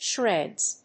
発音記号
• / ʃrɛdz(米国英語)
• / ʃredz(英国英語)